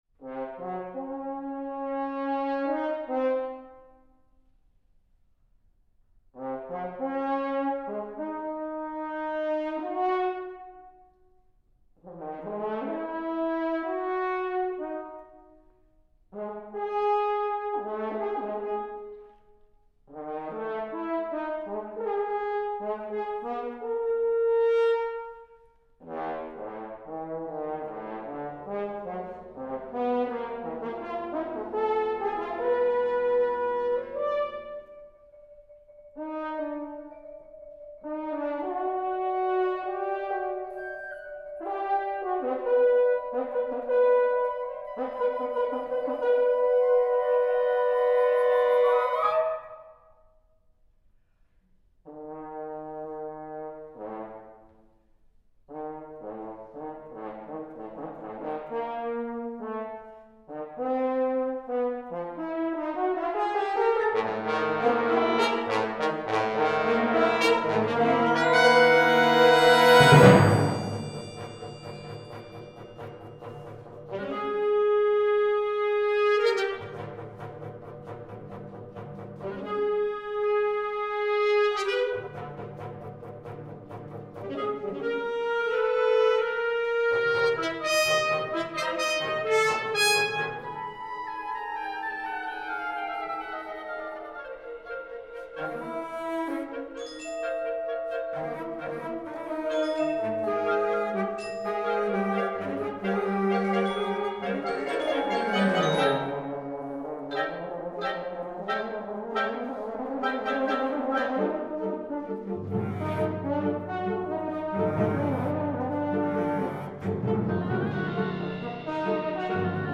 Concerto for Horn and Wind Ensemble: I. Freely [sound recording]
Instrumental Studies Performances (UMKC)